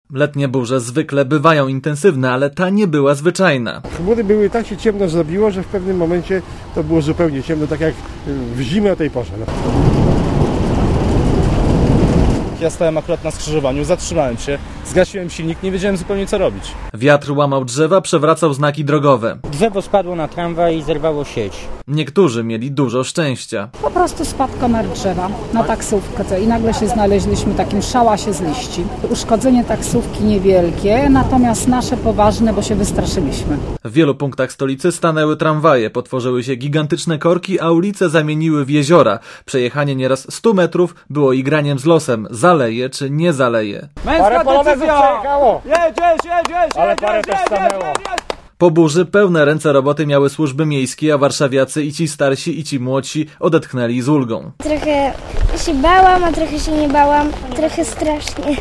Relacja
burza_nad_warszawa.mp3